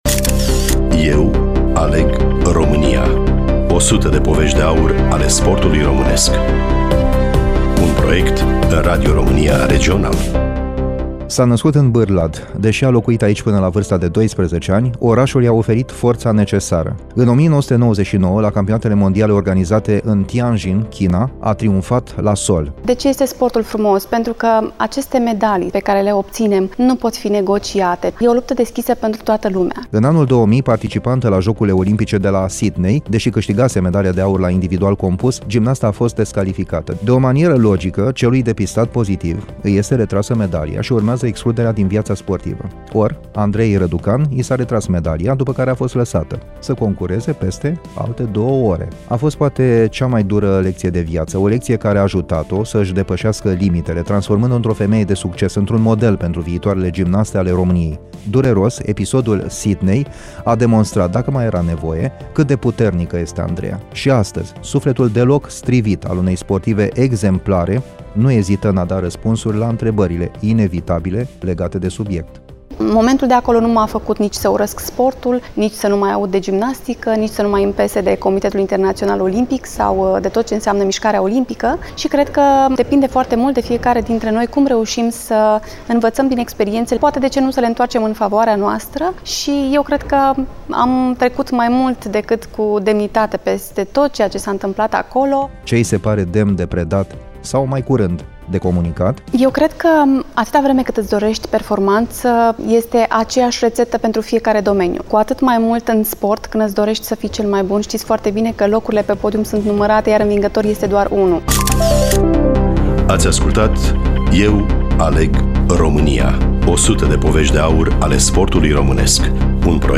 Studioul Radio Romania Iaşi